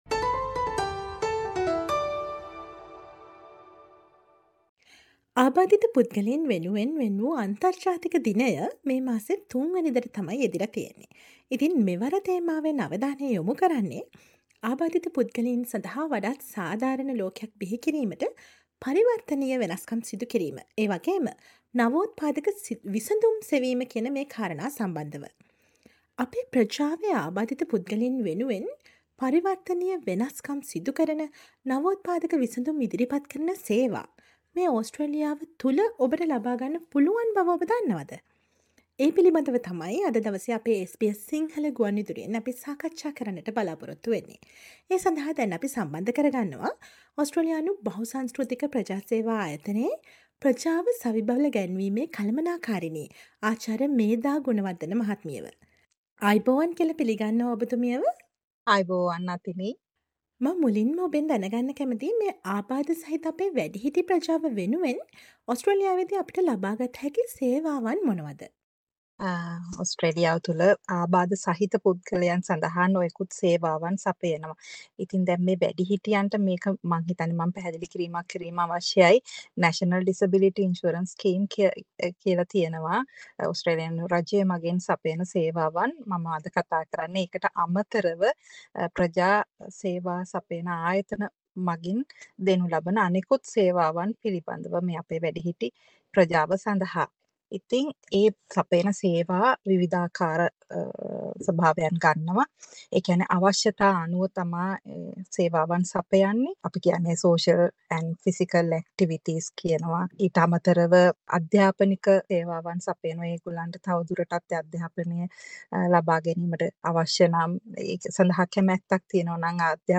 Listen to the SBS Sinhala radio interview